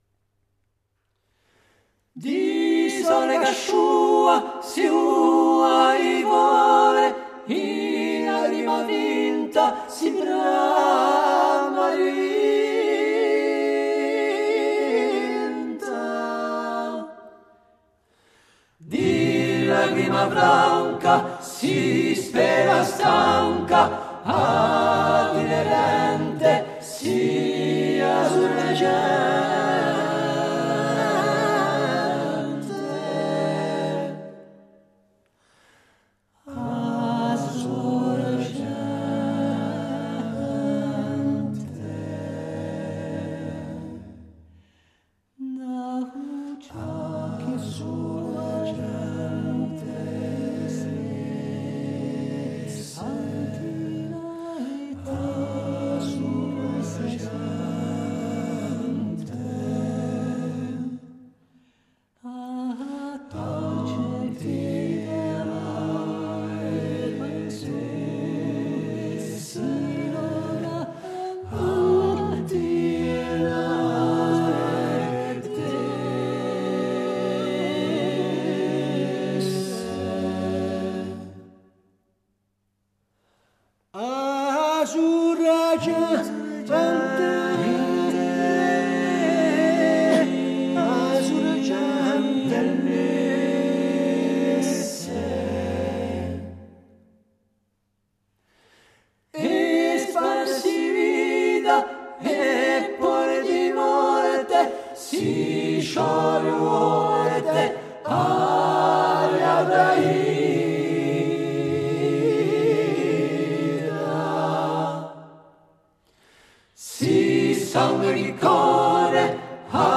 A filetta - interview.mp3